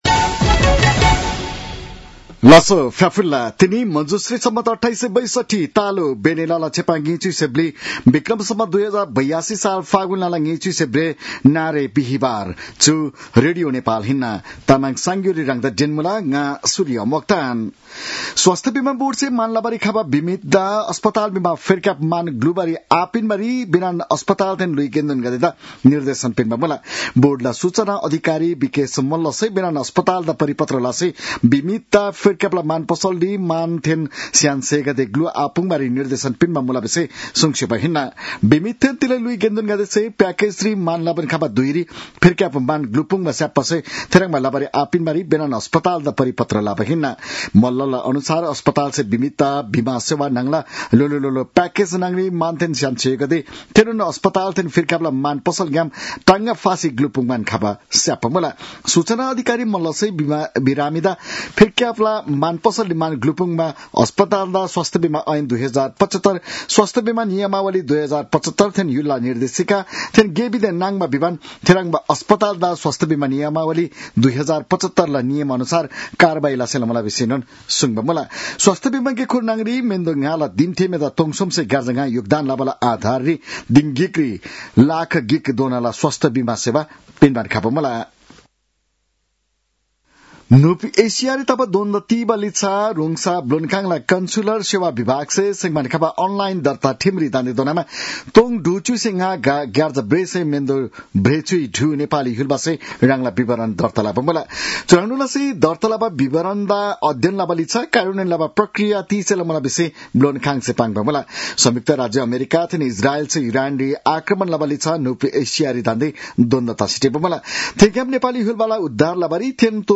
तामाङ भाषाको समाचार : २८ फागुन , २०८२
Tamang-news-11-28.mp3